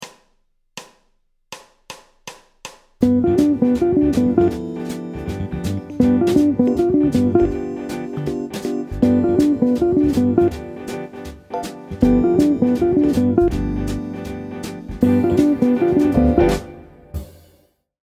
Triade avec saut vers la Quinte et descente vers la Fondamentale.
Phrase 06 – Accord de dominante
Deux motifs de Triade qui s’enchaînent parfaitement et qui utilisent la substitution diatonique à la Tierce de G7 (accord de BØ)